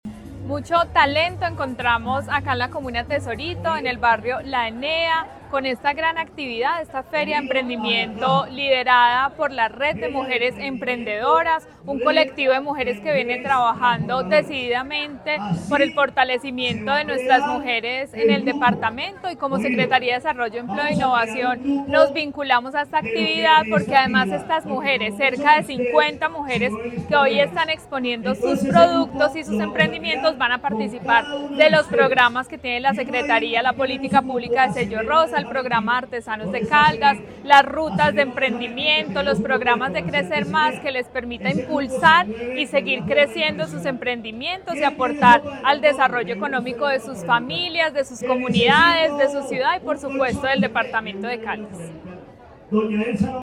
Daissy Lorena Alzate, secretaria de Desarrollo, Empleo e Innovación de Caldas
Daissy-Lorena-Alzate-secretaria-de-Desarrollo-Empleo-e-Innovacion-de-Caldas.mp3